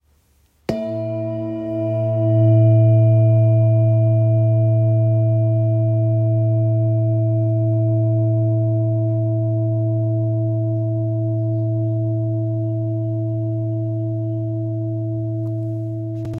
Etched Saraswati Handmade Singing Bowl – 21cm
Expertly hand-hammered by artisans, the bowl produces warm, resonant tones with long-lasting vibrations, designed to calm the mind and open space for clarity and inspiration. Its size allows for a deep, grounding sound that carries throughout a room.
Saraswati-21cm-new-mallet.m4a